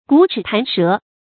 龂齿弹舌 yǔ chǐ dàn shé